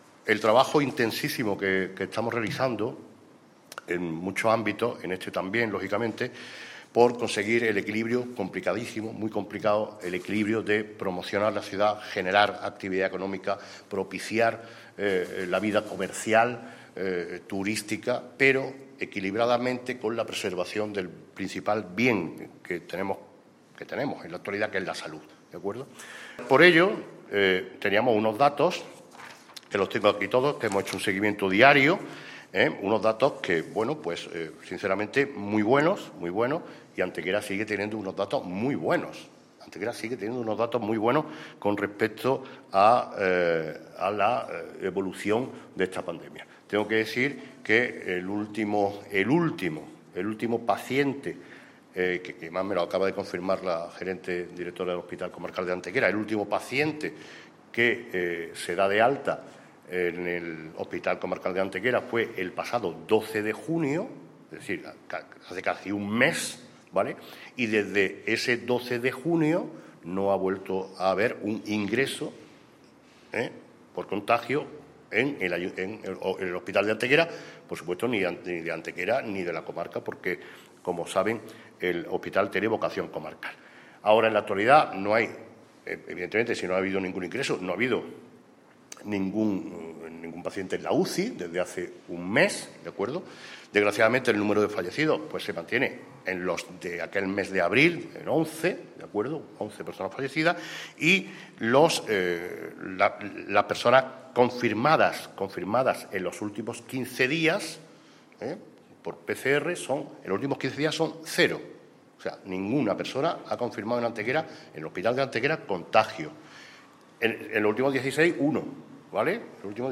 El alcalde de Antequera, Manolo Barón, y el teniente de alcalde delegado de Patrimonio Mundial, Juan Rosas, han anunciado en la mañana de hoy viernes en rueda de prensa la cancelación del Antequera Light Fest (ALF), festival de nuevas tecnologías, luz y sonido que cada año a mediados del mes de julio conmemora en nuestra ciudad la declaración del Sitio de los Dólmenes como Patrimonio Mundial de la UNESCO.
Cortes de voz